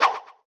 whiff.wav